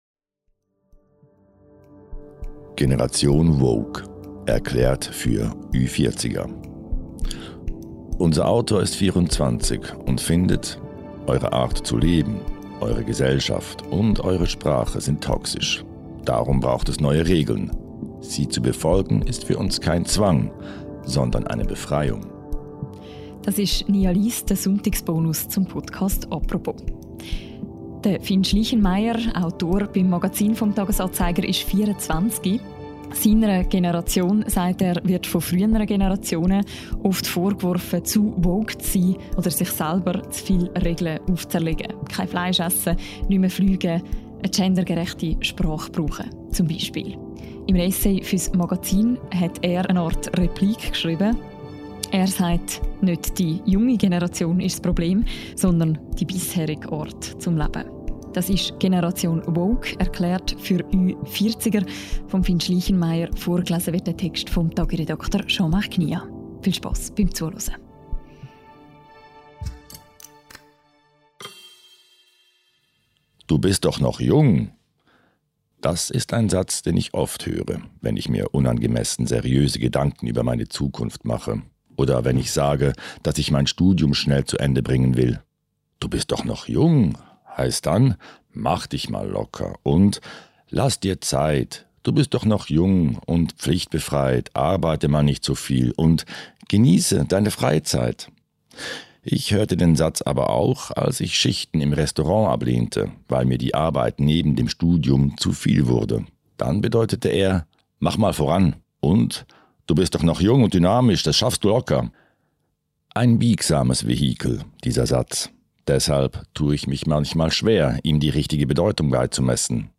Darum braucht es neue Regeln – sie zu befolgen ist für uns kein Zwang, sondern eine Befreiung. Vorgelesen wird sein Text von Tagi